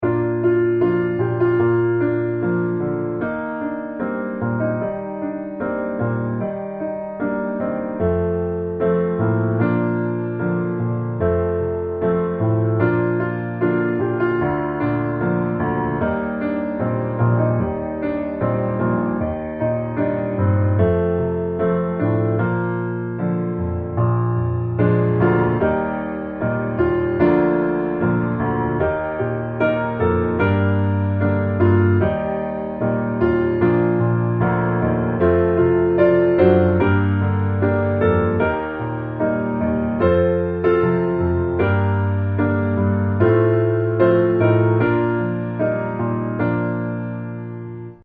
Bb Major